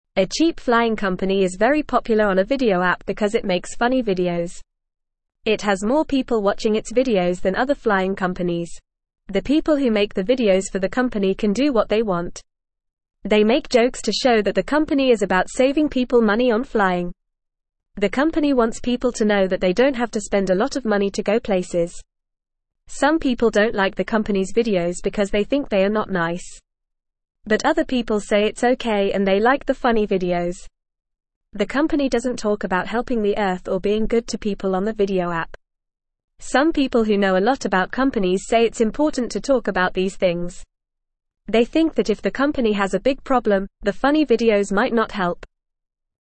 Fast
English-Newsroom-Beginner-FAST-Reading-Funny-Flying-Company-Saves-Money-with-Popular-Videos.mp3